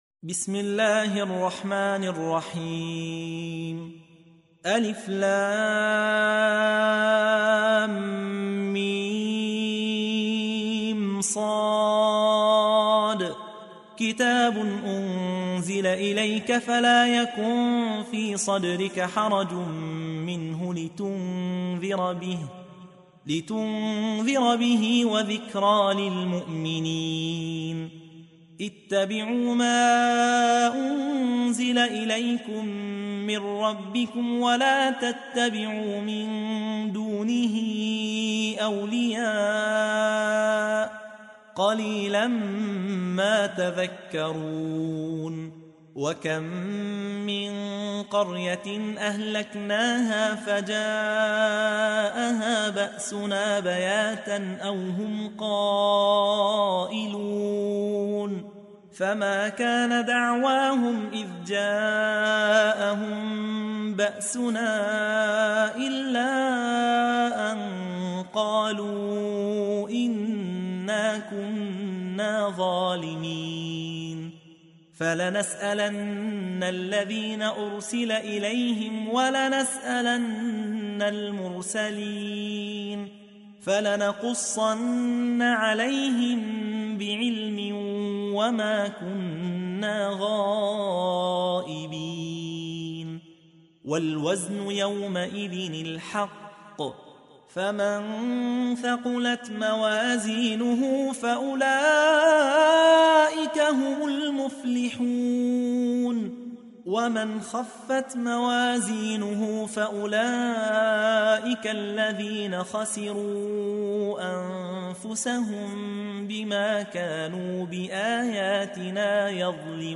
تحميل : 7. سورة الأعراف / القارئ يحيى حوا / القرآن الكريم / موقع يا حسين